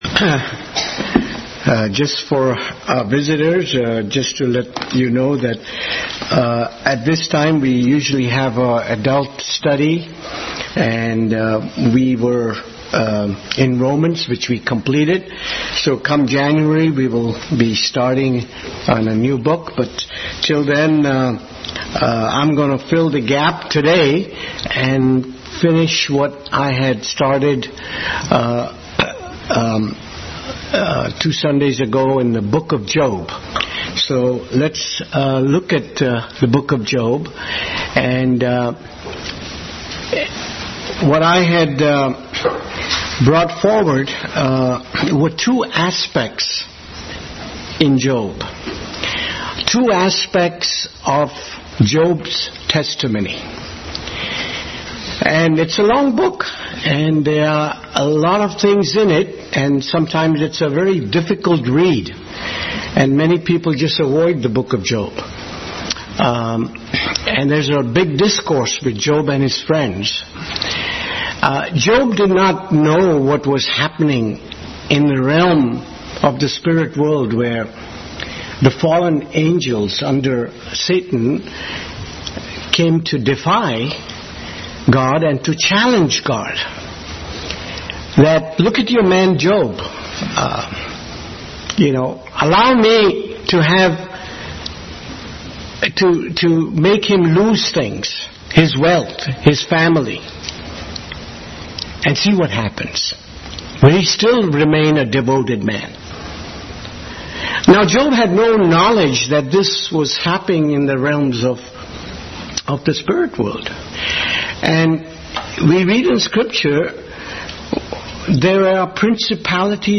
Bible Text: Job 38:2-41, 1 Peter 1:10-12, 1 Kings 19:11-18, Revelation 14:6-7, Hebrews 10:38, 11:3, Acts 14:15-16, 1 Corinthians 15 43-44 | Adult Sunday School clas continued study of Job’s testimonies.
Service Type: Sunday School